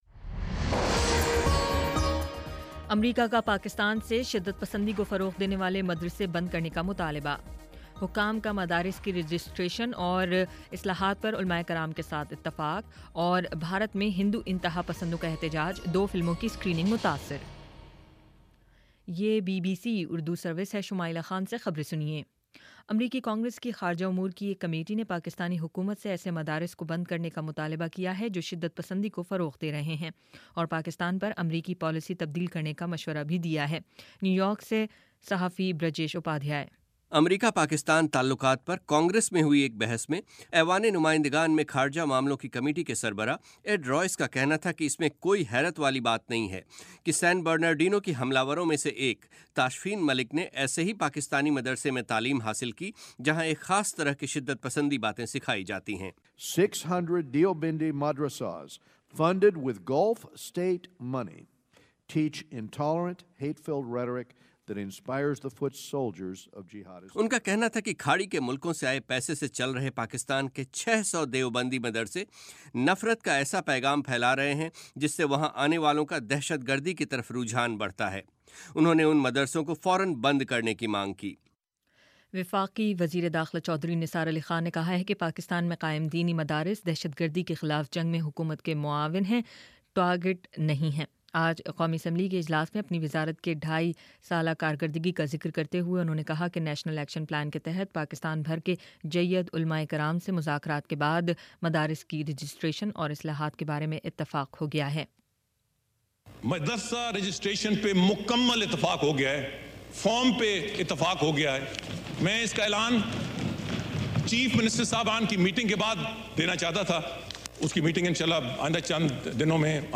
دسمبر 18: شام سات بجے کا نیوز بُلیٹن